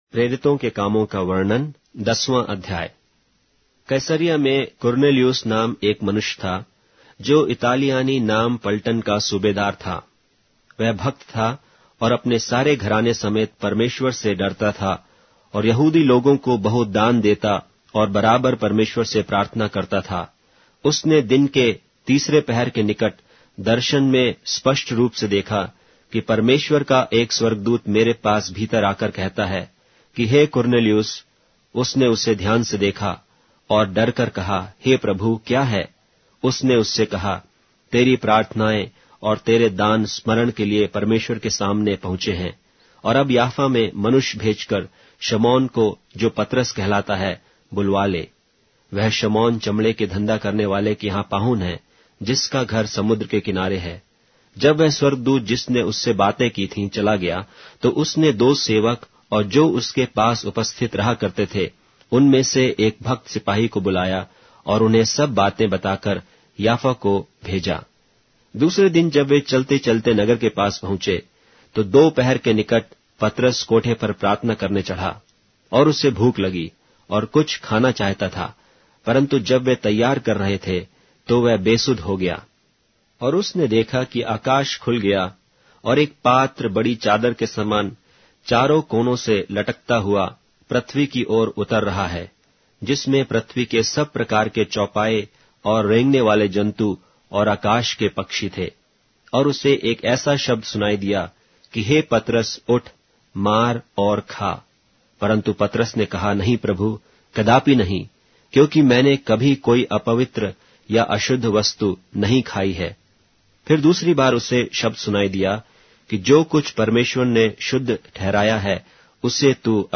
Hindi Audio Bible - Acts 27 in Wlc bible version